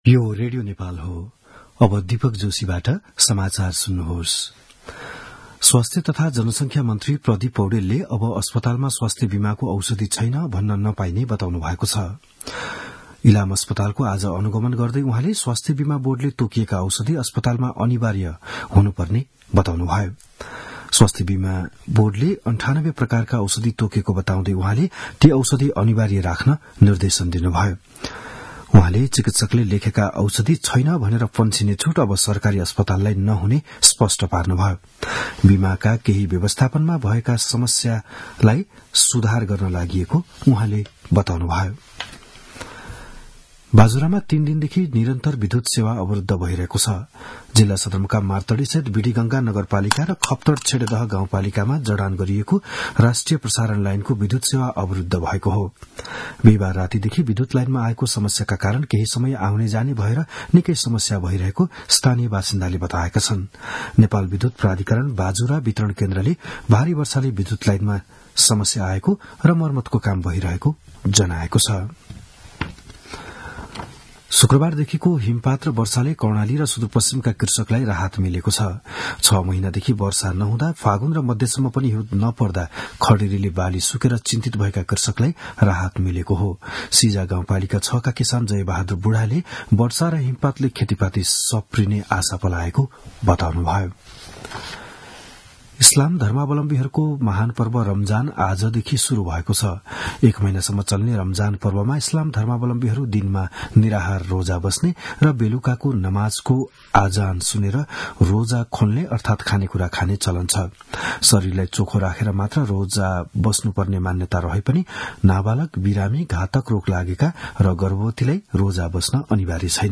बिहान ११ बजेको नेपाली समाचार : १९ फागुन , २०८१
11-am-news-.mp3